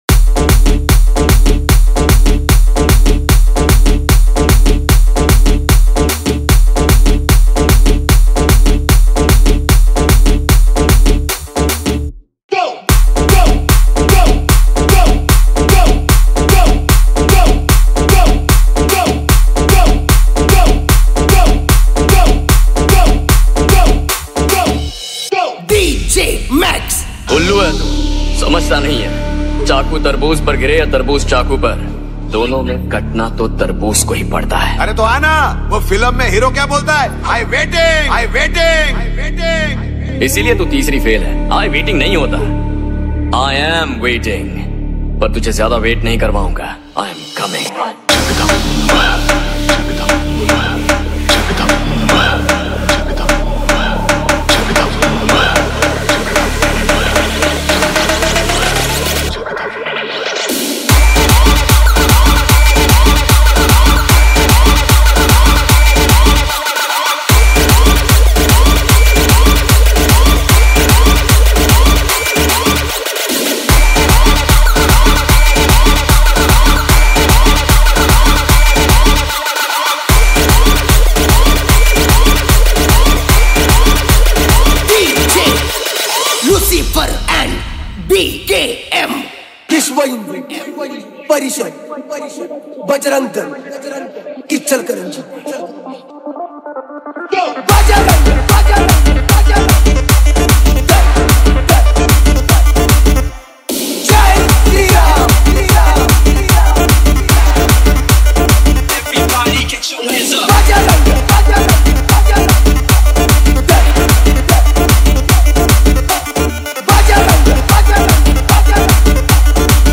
Ram Navami Special Dj Songs Download